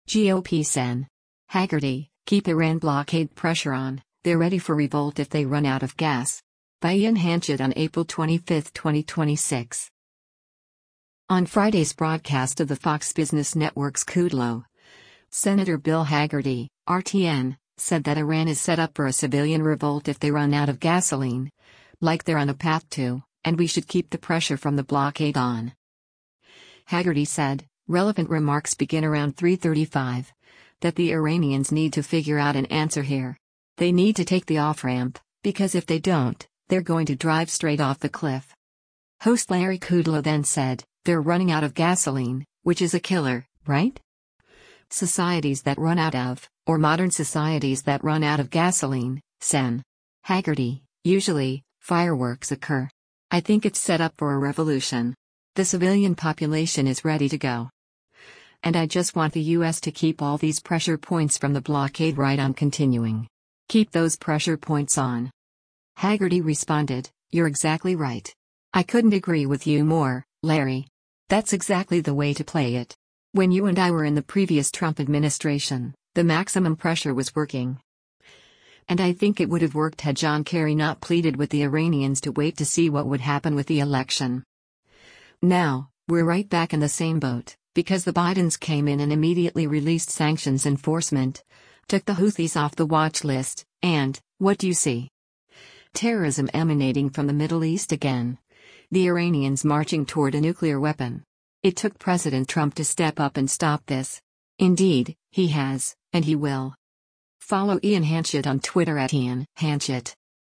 On Friday’s broadcast of the Fox Business Network’s “Kudlow,” Sen. Bill Hagerty (R-TN) said that Iran is set up for a civilian revolt if they run out of gasoline, like they’re on a path to, and we should keep the pressure from the blockade on.